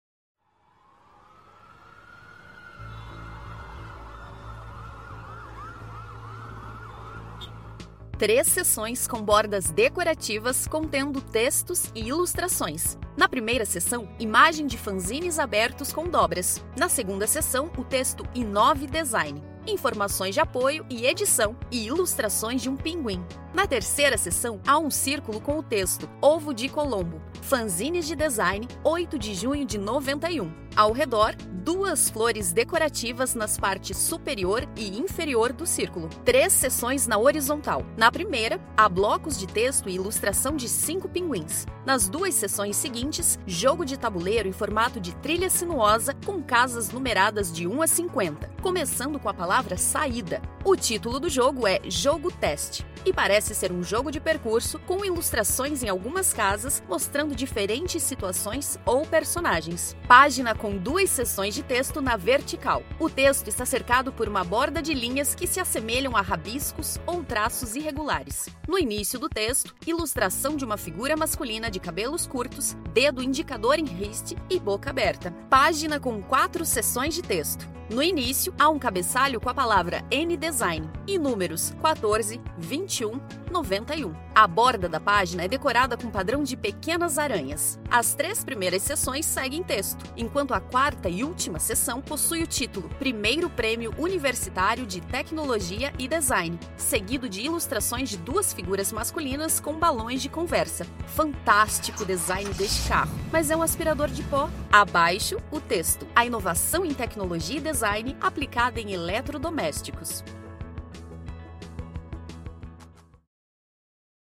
Audiodescrição do Fanzine n° 8